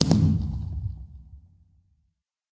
largeBlast_far1.ogg